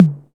Index of /90_sSampleCDs/Roland - Rhythm Section/DRM_Drum Machine/KIT_TR-606 Kit
TOM 606 TOM1.wav